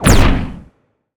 pgs/Assets/Audio/Magic_Spells/energy_blast_small_01.wav at master
energy_blast_small_01.wav